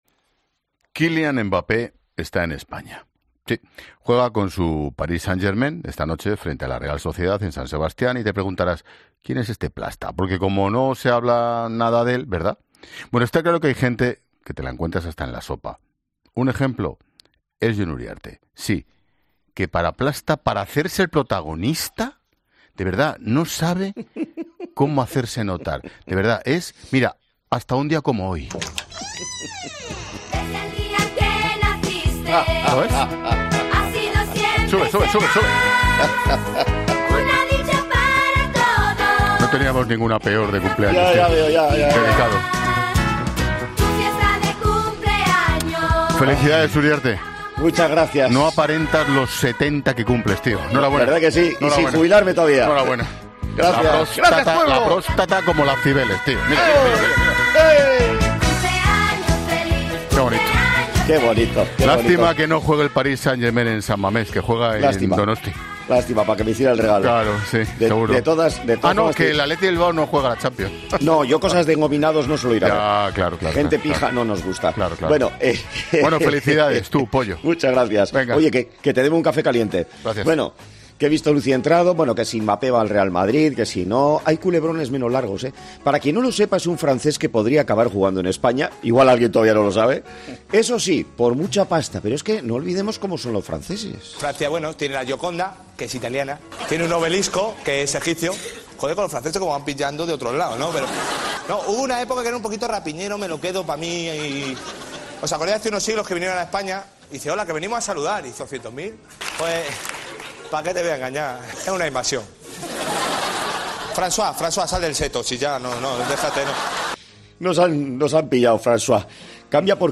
El director de La Linterna, Ángel Expósito y el comunicador, Jon Uriarte hablan sobre las personas a las que les gusta hacerse las protagonistas